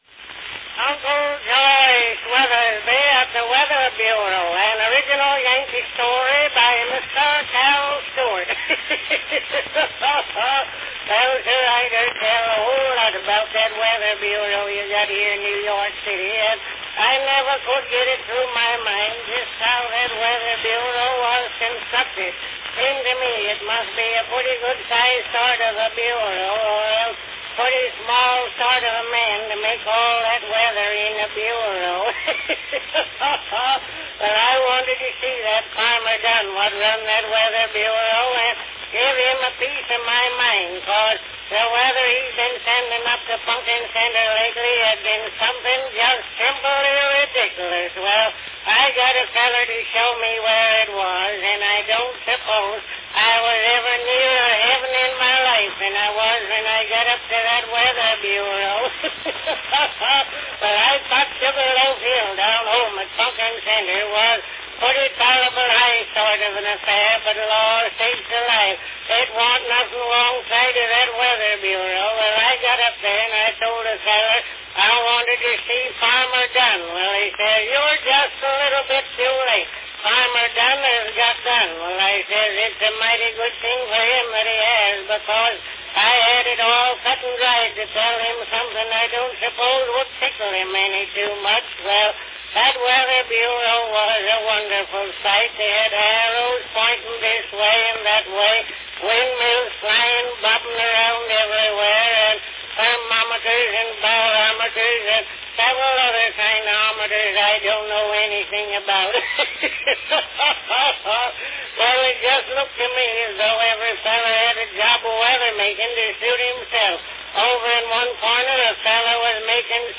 Company Edison's National Phonograph Company
Category Funny talk
Performed by Cal. Stewart
Announcement "Uncle Josh Weathersby at the Weather Bureau, an original Yankee story by Mr. Cal Stewart."